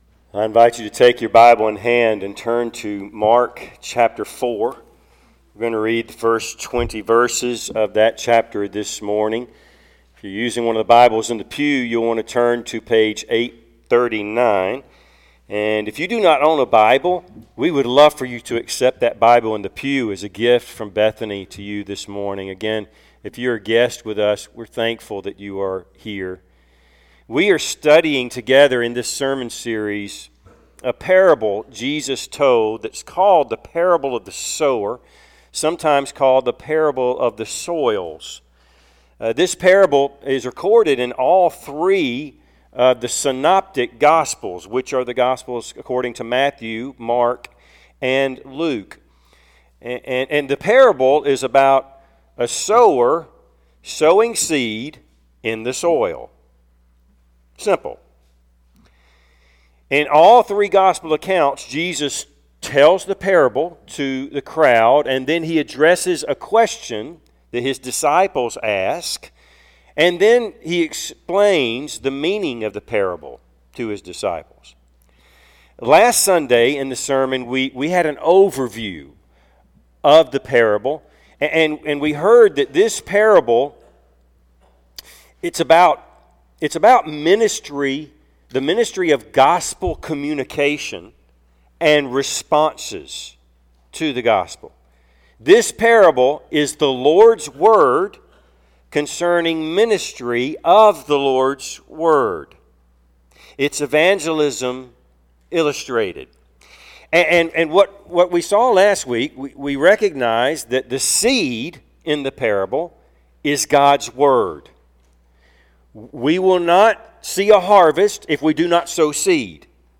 Service Type: Sunday AM Topics: Evangelism , God's Word , Salvation